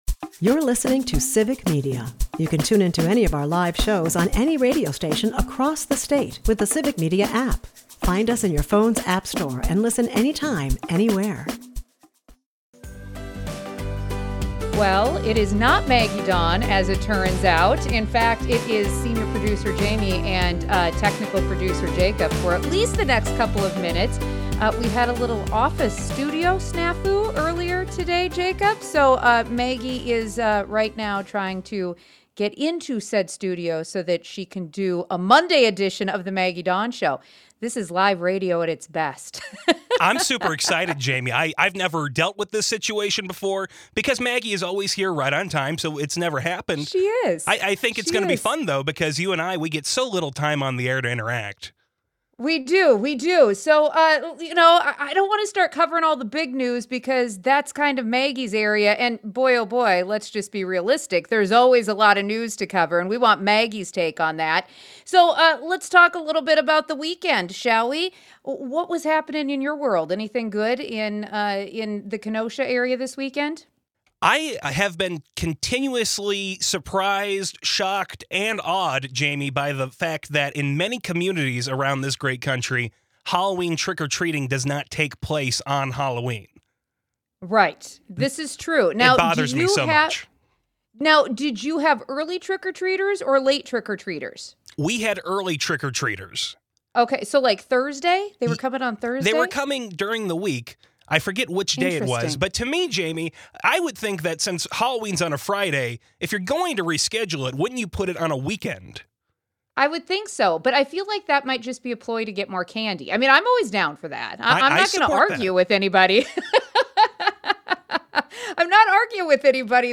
Listeners join in to call out the hypocrisy, demand accountability, and push for meaningful reforms — from economic justice to national healthcare.